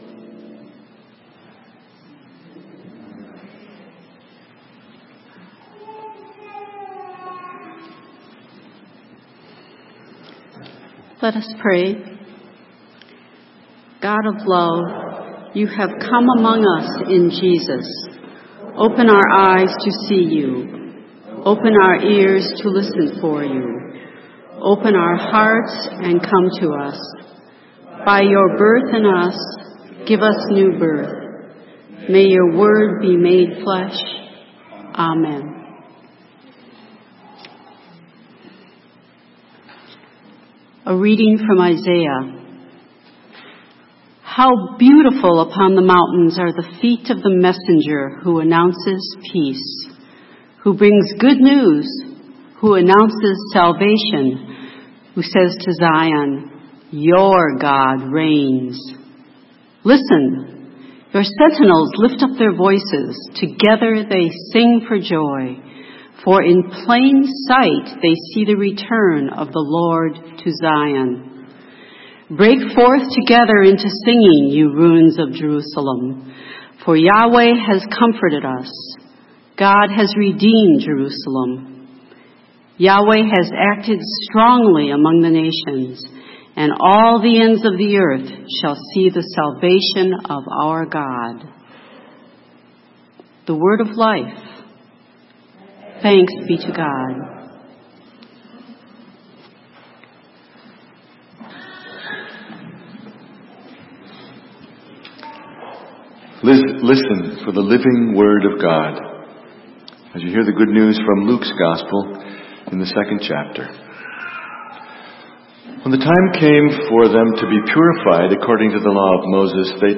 Sermon: What do you see? - St. Matthew's UMC